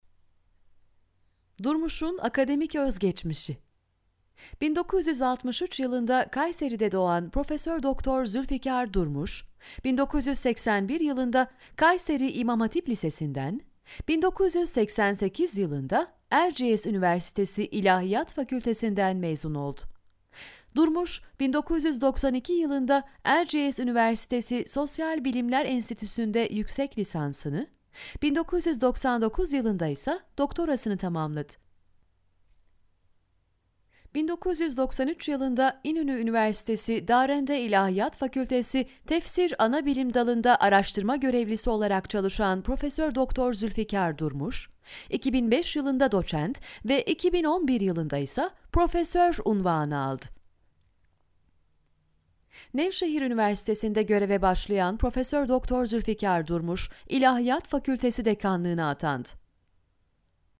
Hello I am professional Turkish voice artist.
I have my own recordig studio.
Kein Dialekt
Sprechprobe: Sonstiges (Muttersprache):